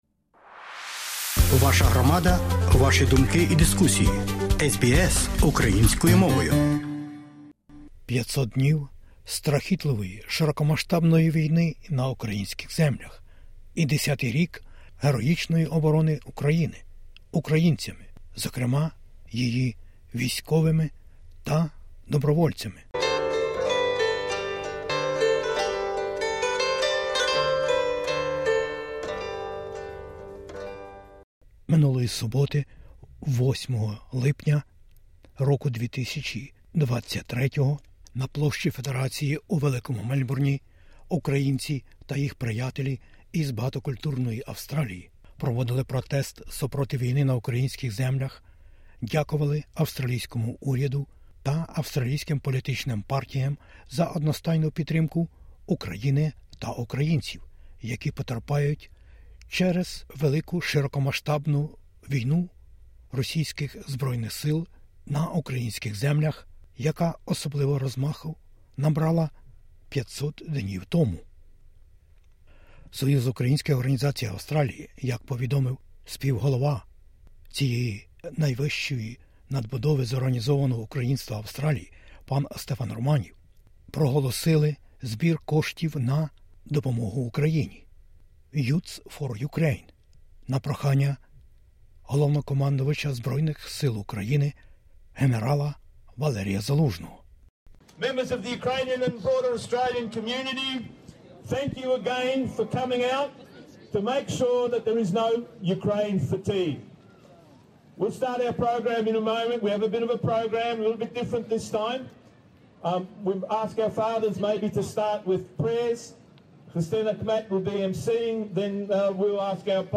Australian-Ukrainian community rallies in Melbourne on Saturday 8 July 2023.